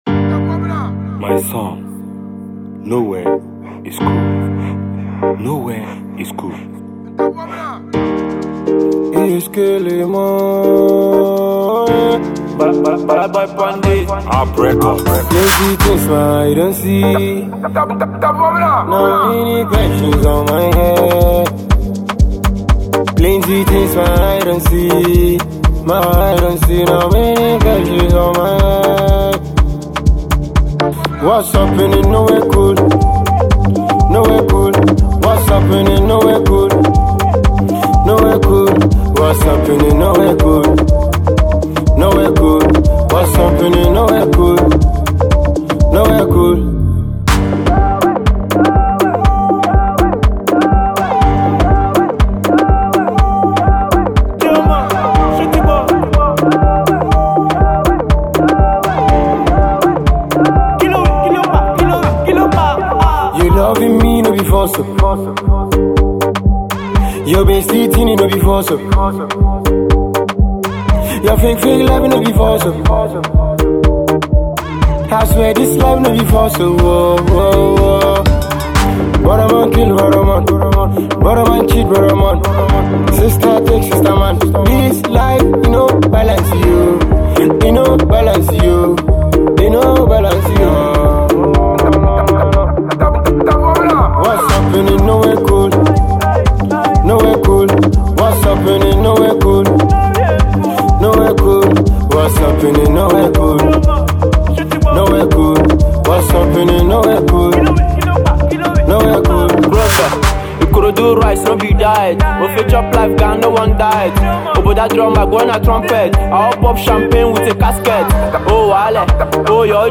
afrobeats singer